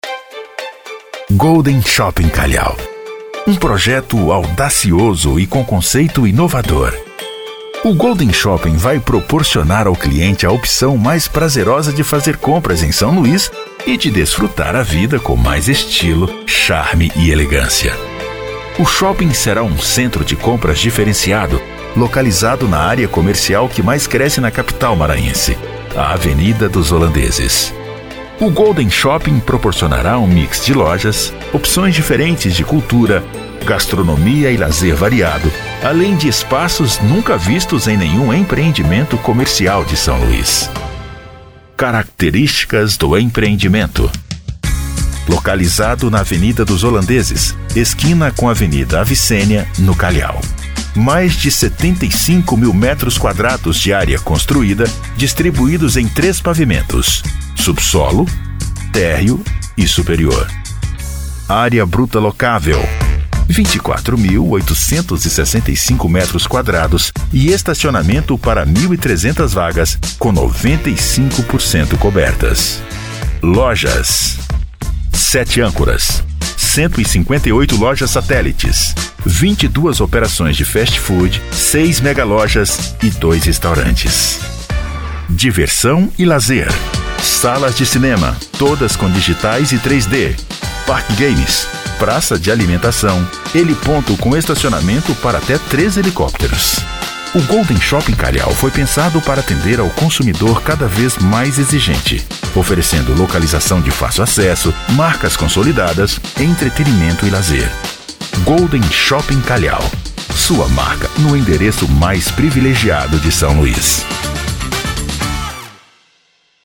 DMVoz Produções - Locução profissional